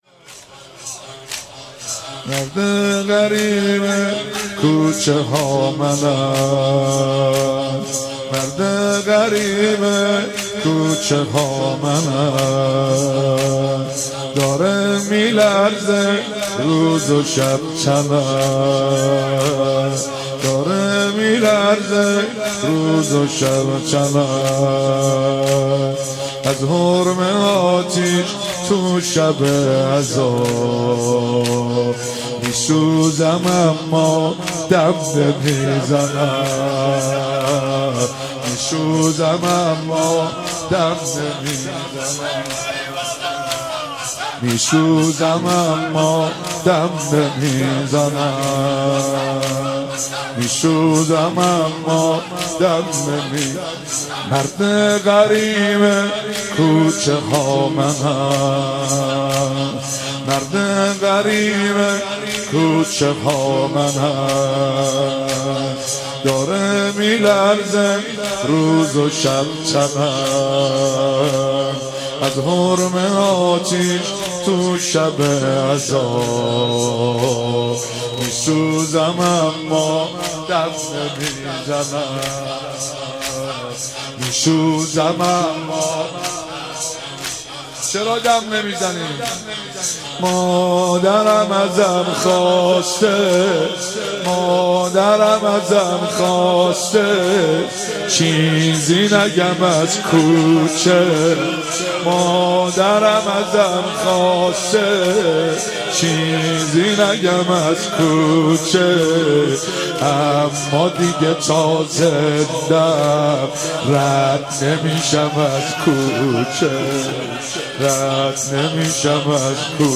27 بهمن 95 - زمینه - مرد غریب کوچه ها منم